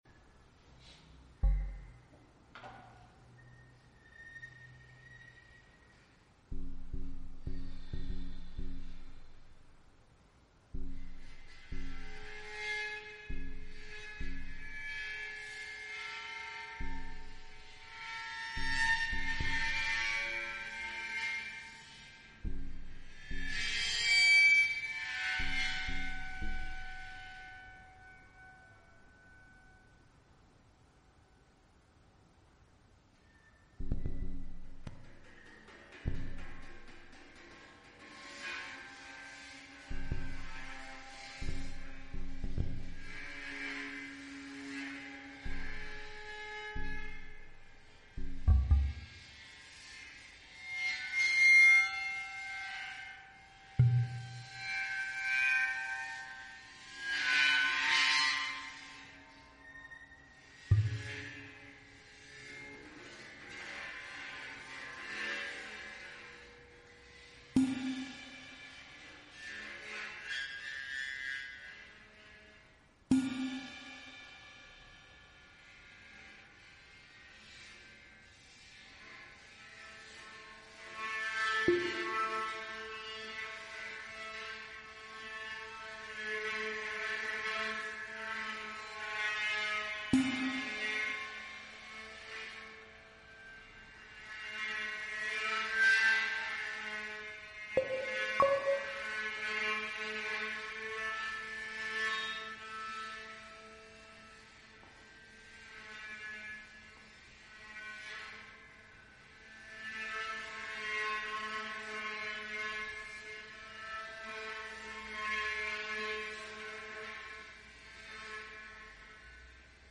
A body and sound live collaboration-improvisation
bobni = percussion